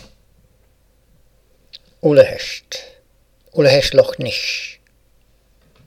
Short audio clip reading my username and then the Gaelic name of the Loch Ness Monster in my exclusive Gaelic with an Italian accent. It sounds a bit like "uh-le-hest" (and "uh-le-hest loch nish") 🗒  Attached To /notes/9guozp9c30ph00cg